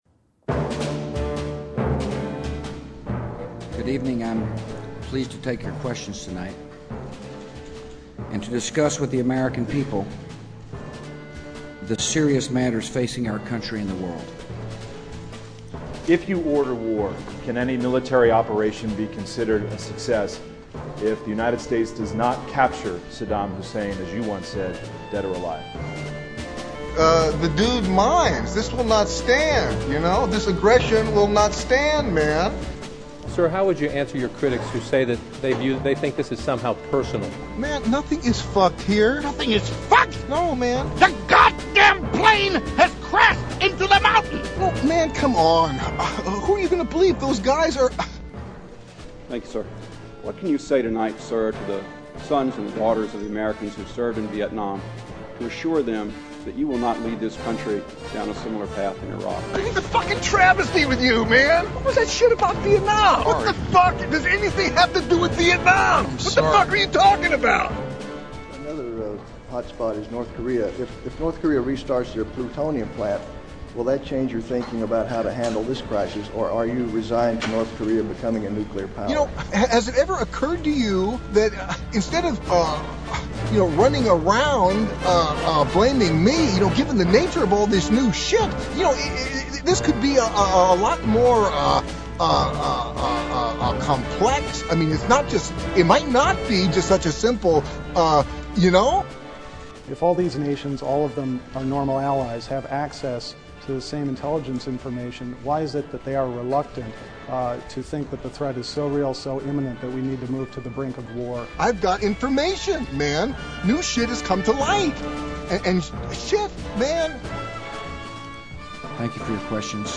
G.W. "Dude"'s Iraq War Press Conference